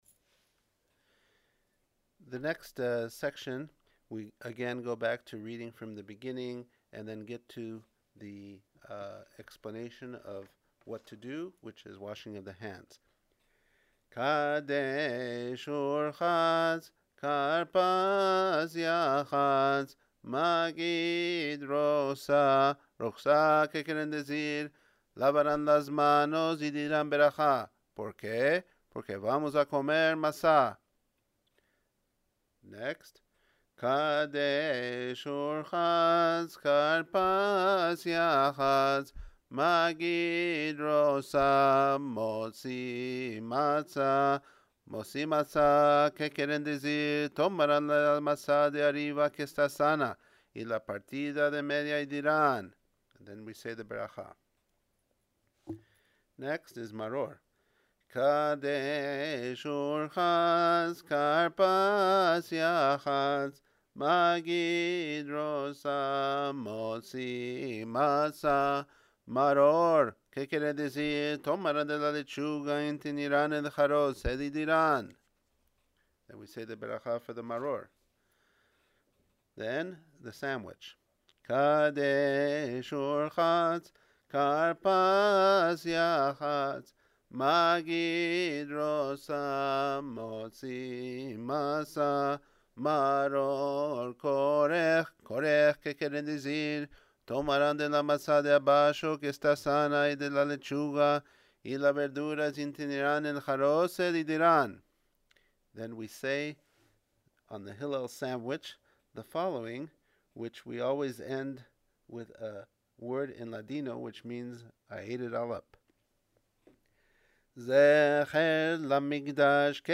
we now can hear the words, melodies and tones of the Haggadah as it’s been chanted by our parents and grandparents in the Rhodesli tradition.